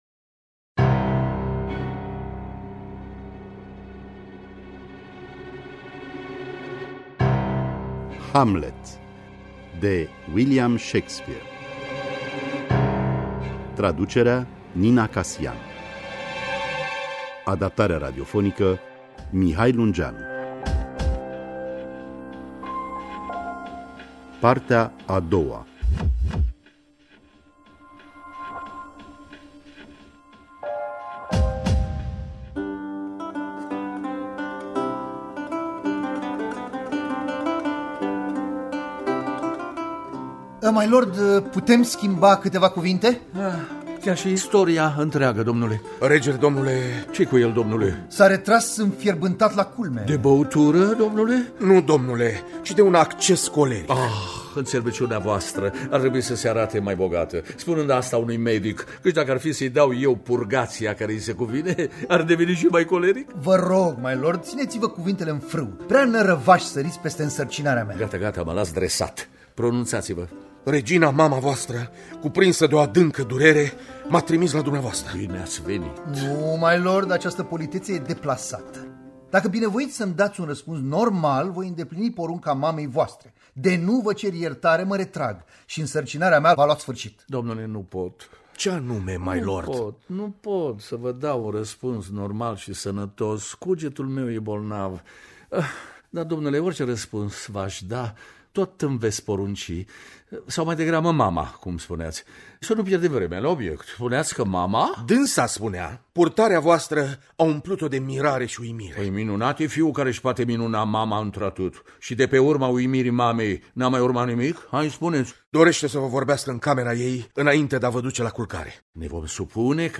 Adaptarea radiofonică
La pian: Dan Grigore.